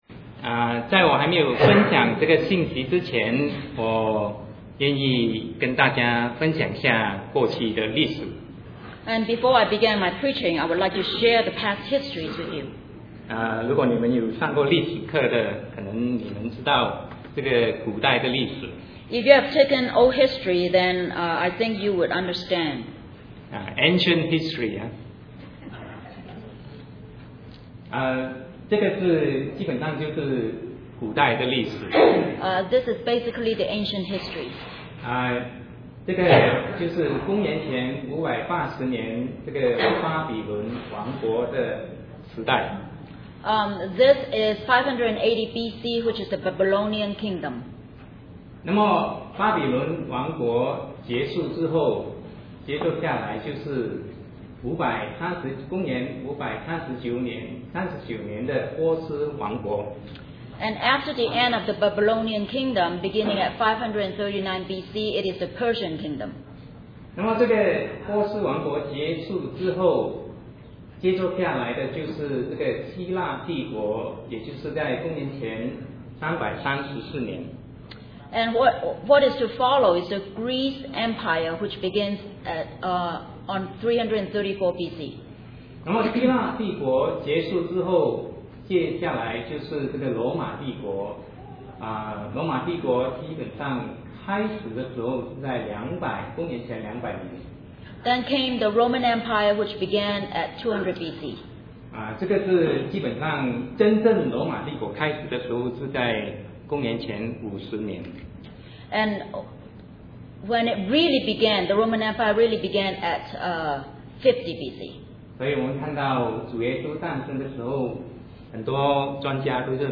Sermon 2009-01-11 What Does the Bible Say about the European Union?